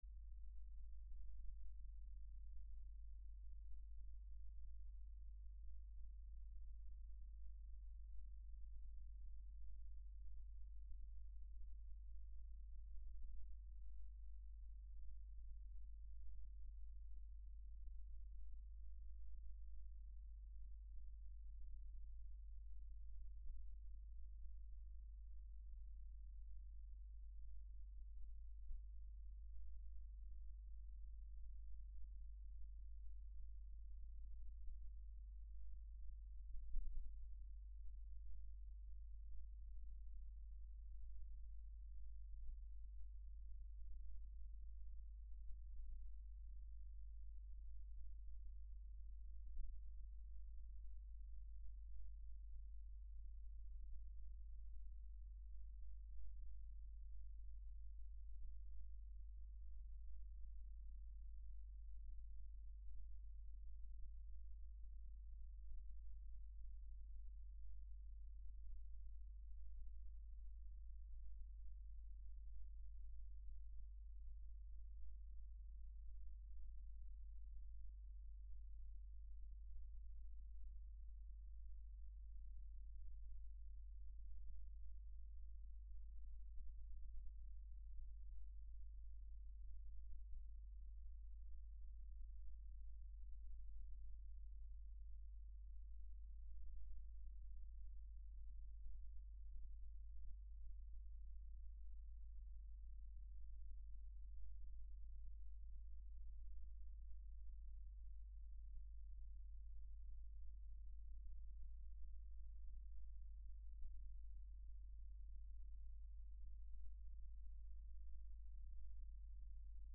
Geomagnetic pulsation
Strong and clear geomagnetic pulsation detected with induction coil, inside the red ovals.
Source files: 2015-01-09 00:00 to 04:00 and 04:00 to 08:00 at OpenLab st. 04 - Sogliano al Rubicone(FC), N Italy